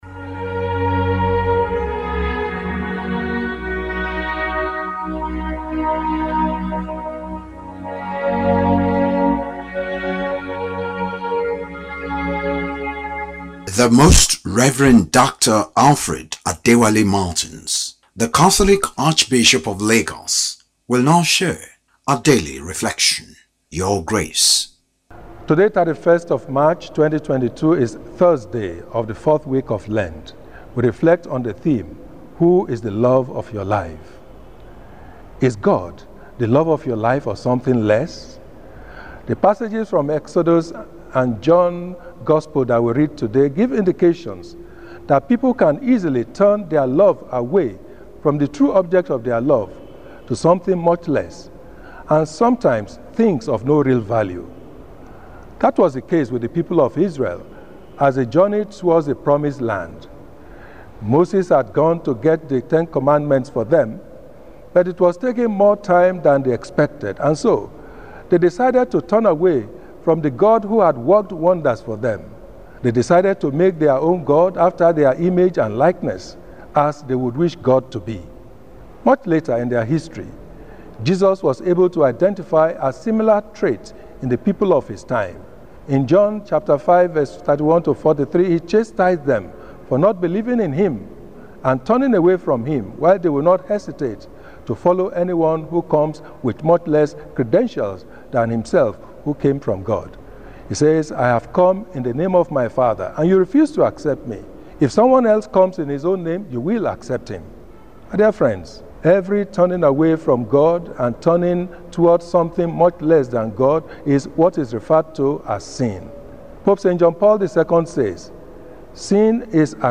Lenten-Talk- THURSDAY